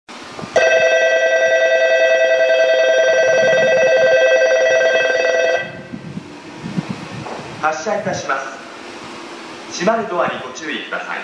駅放送
発車 音量が小さい時がある 一部下り接近放送と被っていますがご了承下さい。